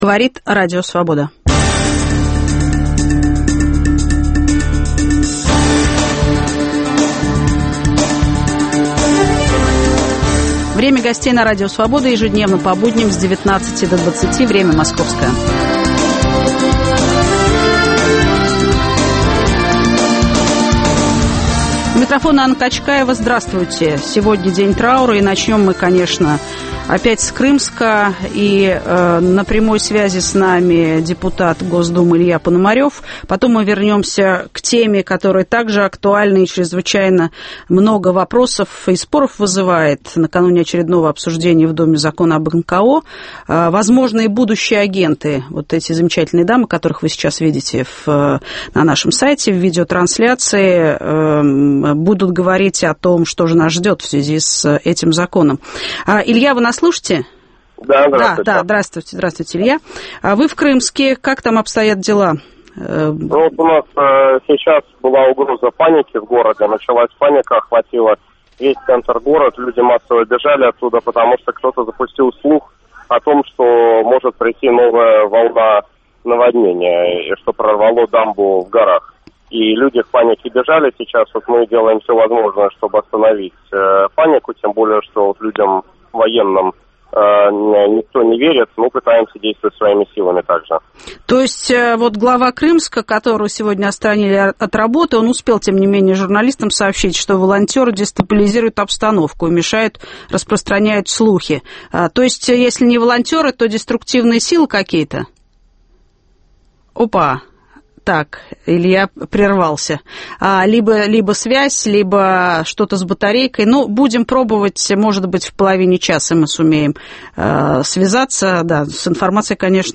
Взгляд очевидца: депутат Илья Пономарев из Крымска.